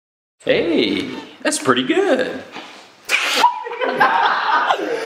meme